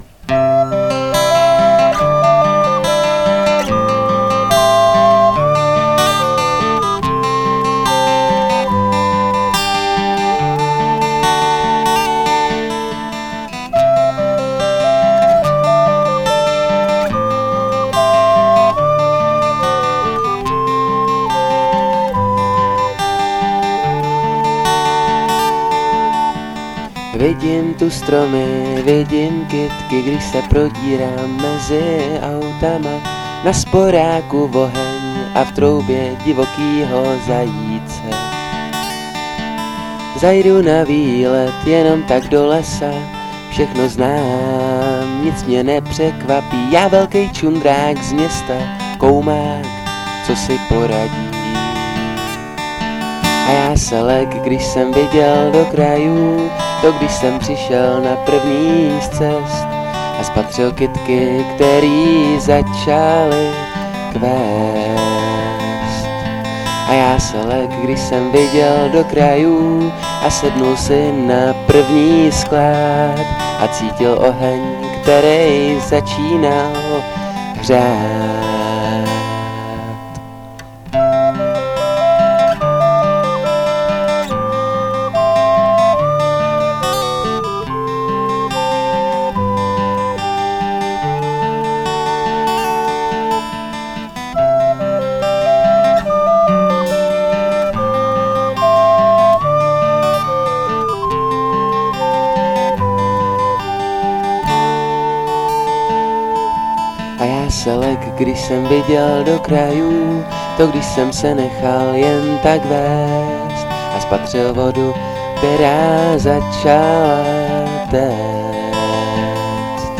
Jeden za všechny -folk
Můj první pokus něco nahrát (asi v 17 letech), zvuk je dost hrozný (měl jsem nějaký hrozný počítačový mikrofon), navíc někdy falešný...:-) Ale je to upřímný (někdy až moc). Na nahrávkách hraju na všechno já (kytara, basa, flétna, housle, perkuse) – proto i název Jeden za všechny.